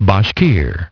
Transcription and pronunciation of the word "bashkir" in British and American variants.